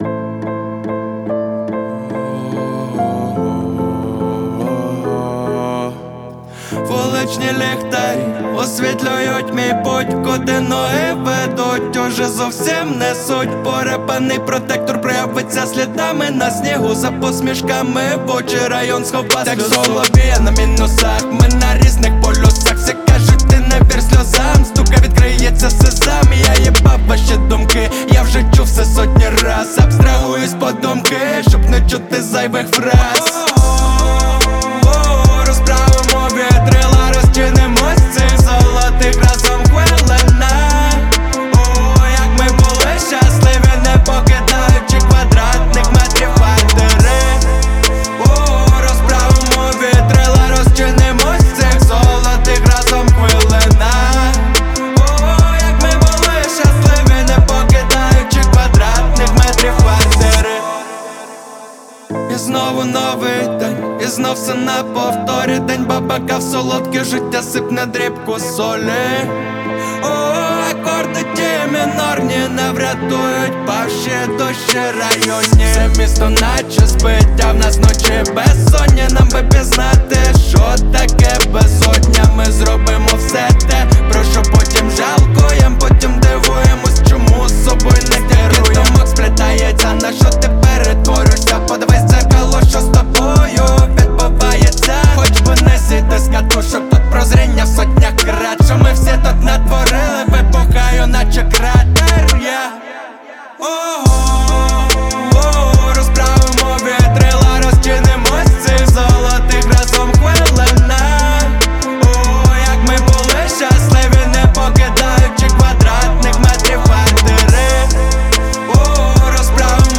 • Жанр: Rap, Hip-Hop